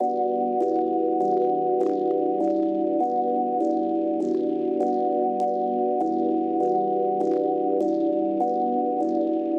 活着的X钢琴说唱
描述：这个钢琴，是我的新歌，叫做，说唱器乐节拍，你可以使用，但要告诉我，好吗。
标签： 100 bpm Rap Loops Piano Loops 1.62 MB wav Key : C
声道立体声